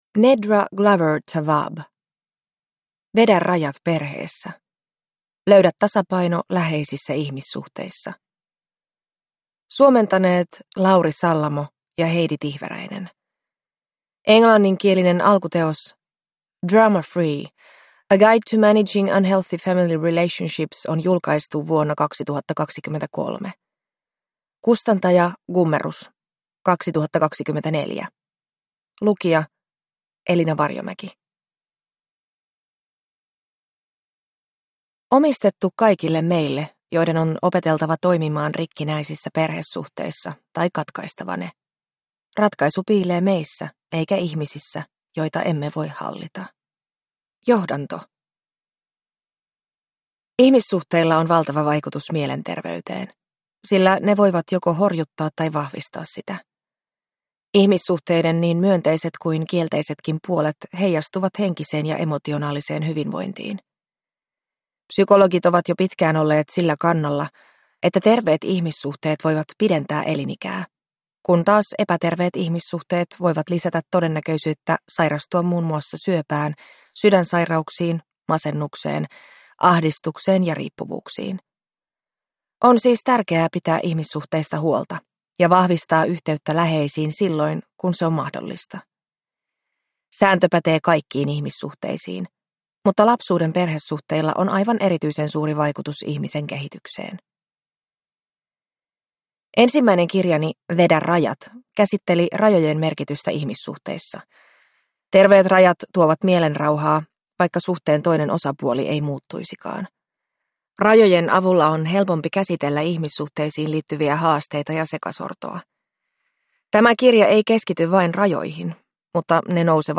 Vedä rajat perheessä (ljudbok) av Nedra Glover Tawwab